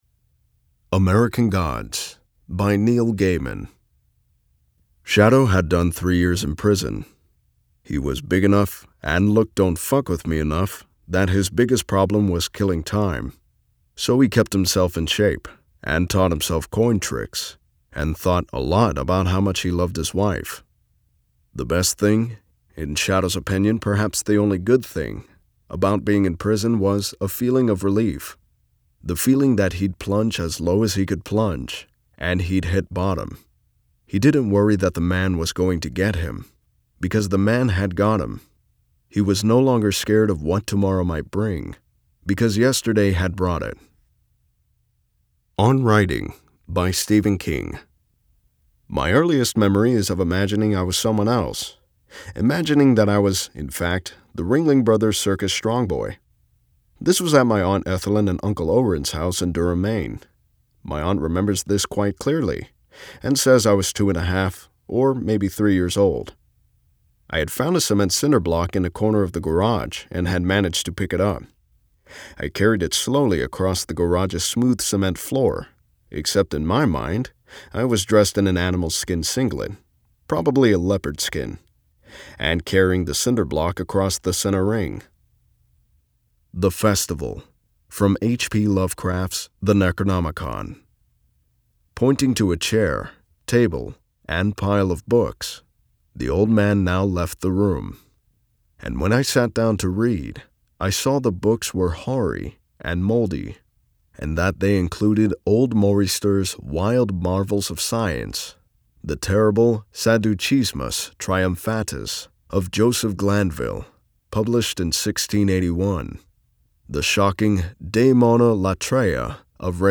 Male
Audiobooks
Words that describe my voice are Warm, Deep, Natural.